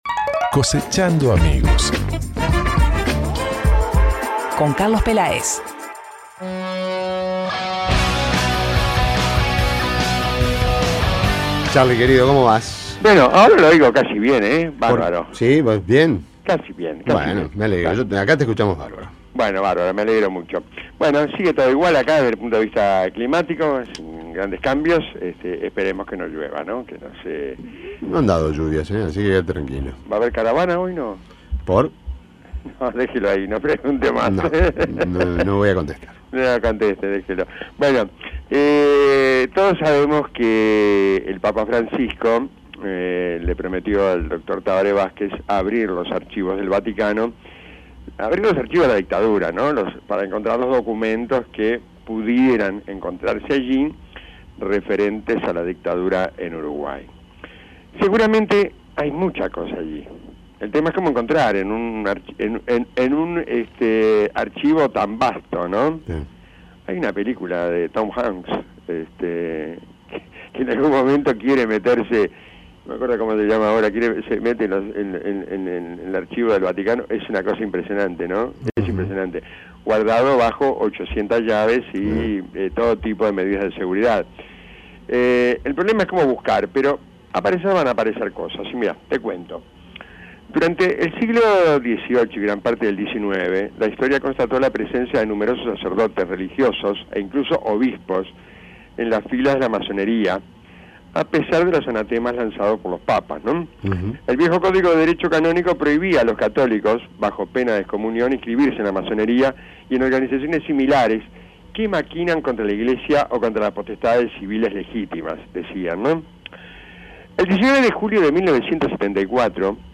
Palabras por el micrófono de Radio El Espectador